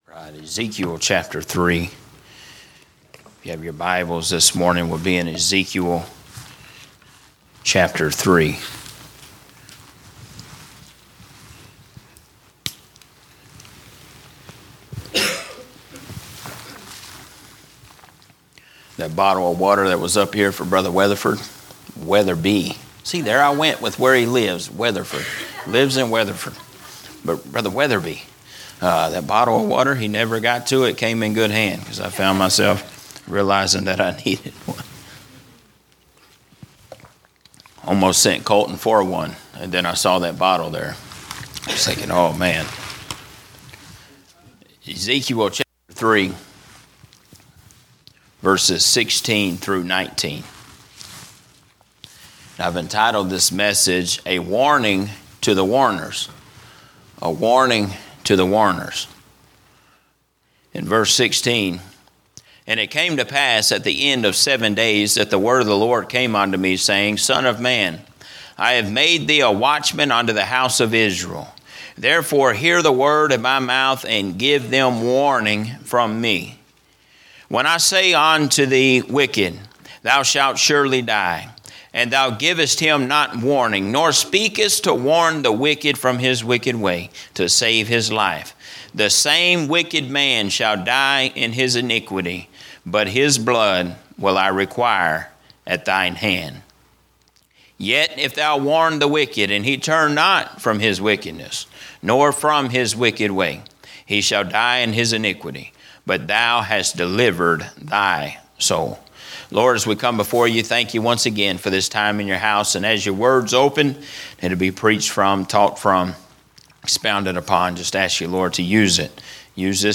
Sermons preached during our annual missions revival